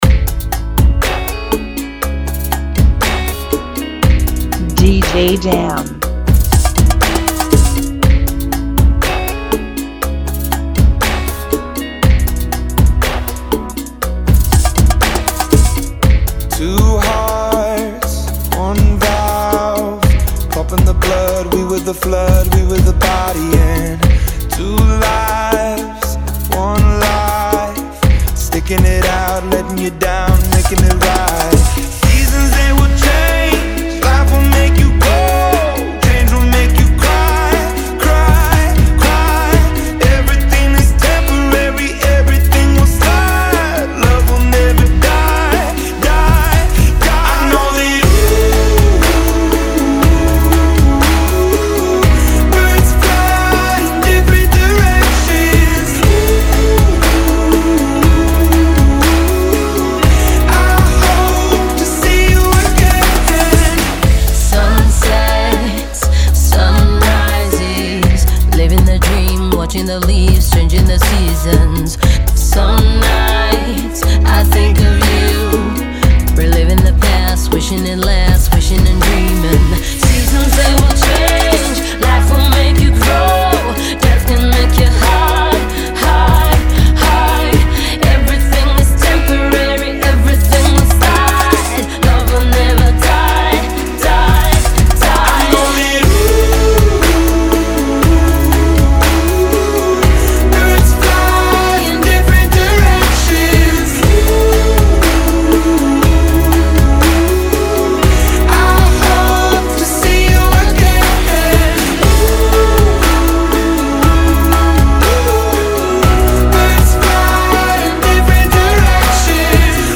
Genre: Bachata Remix